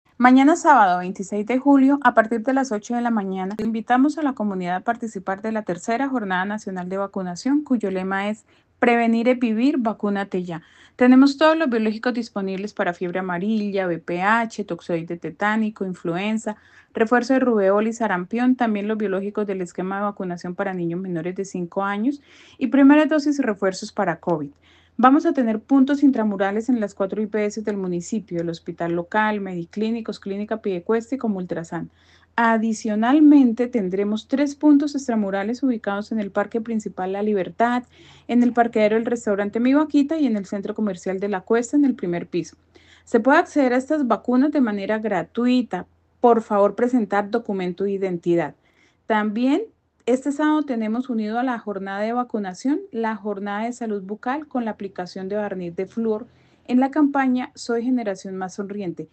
Magda Rodríguez, secretaria de salud de Piedecuesta